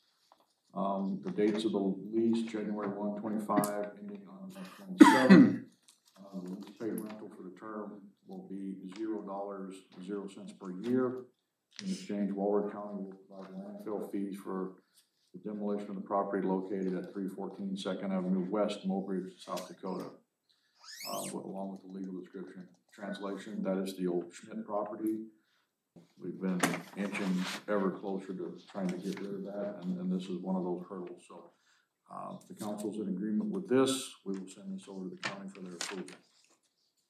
Council passed a motion to approve the revision in the office lease agreement with Walworth County.  Mayor Gene Cox talked about the revision.